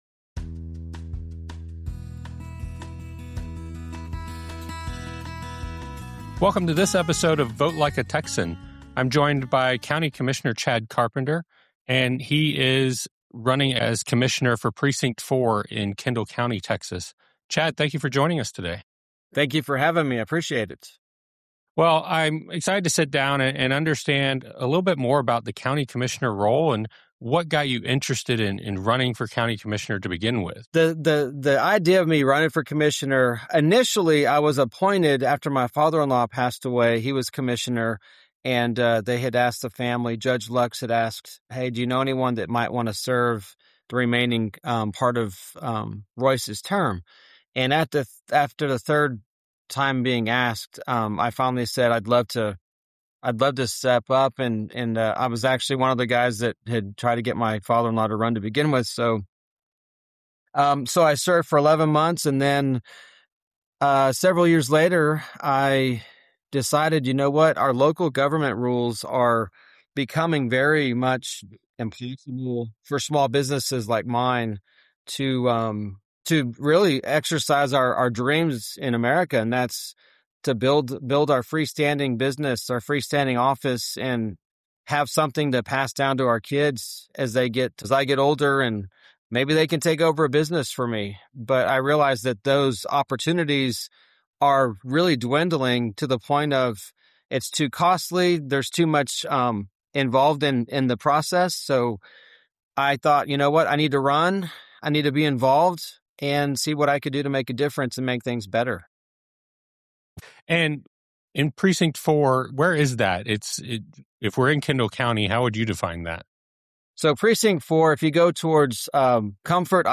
In this interview, we go beyond party politics to discuss what actually impacts your daily life: Water Crisis: The reality of groundwater management and availability.